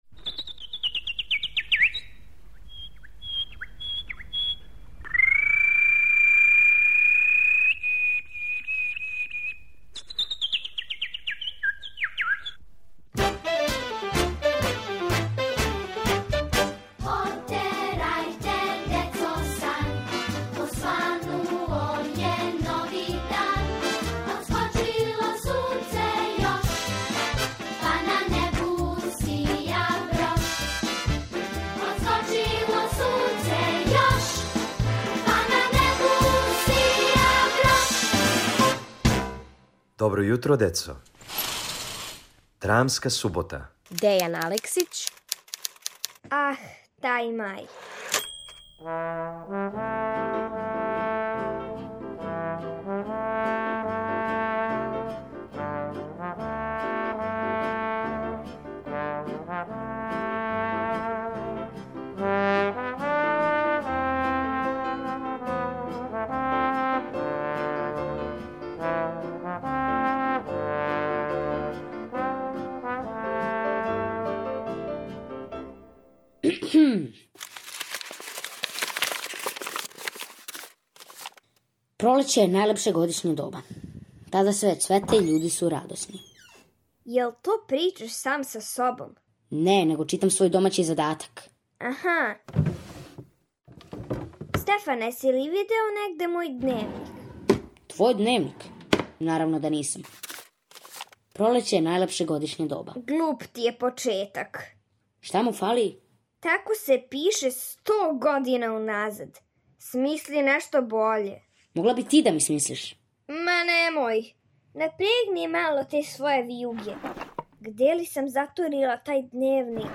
Откуд мај у априлу (па макар био и на самом крају) сазнајте у овој краткој драми Дејана Алексића.